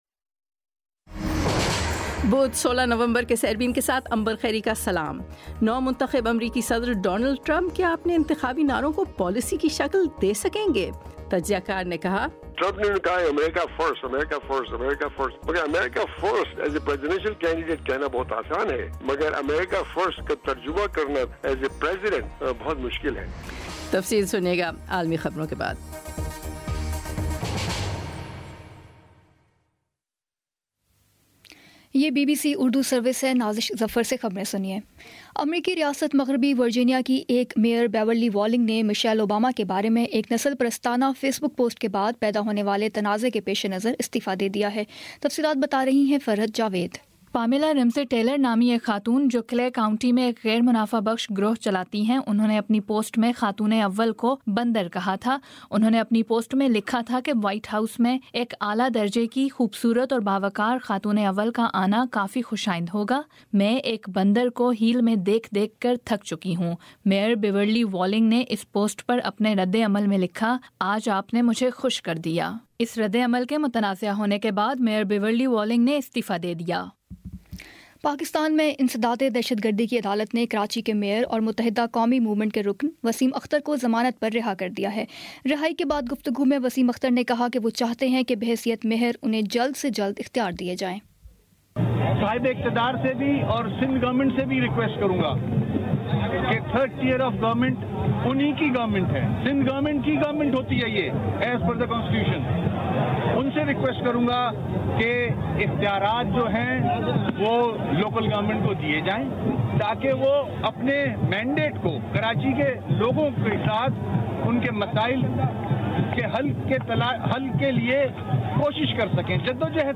بدھ 16 نومبر کا سیربین ریڈیو پروگرام